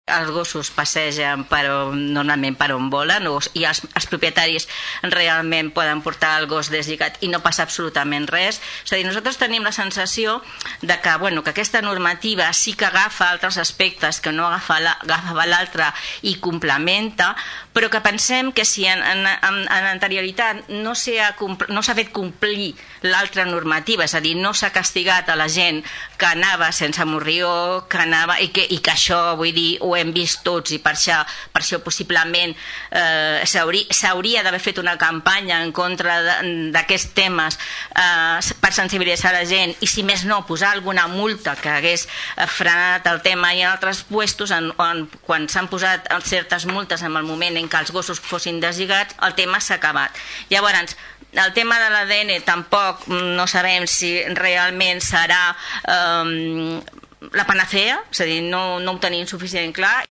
Des del PSC, la regidora Carme Fernández apuntava que, a banda de la problemàtica dels excrements, també és important regular el fet que els propietaris dels gossos els portin deslligats i sense morrió, la qual cosa no s’ha fet.